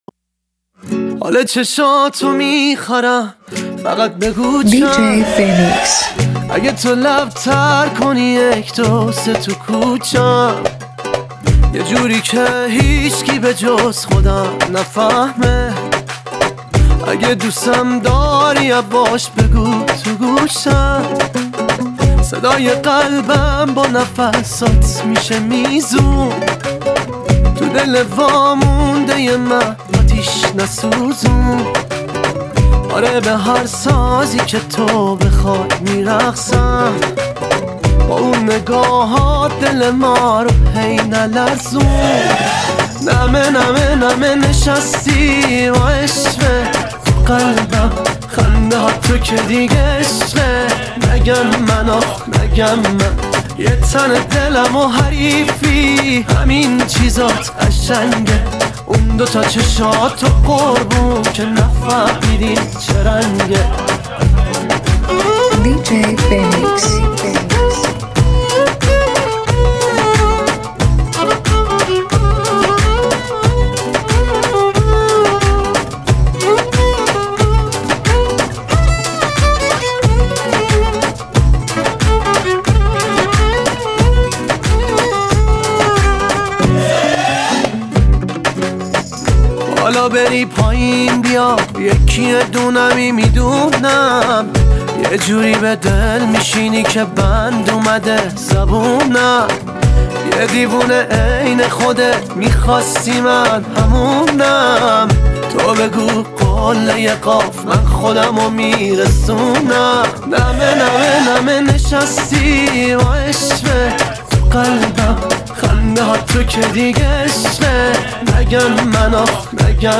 ریتم‌های تند و پرانرژی